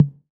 BBONGO LW.wav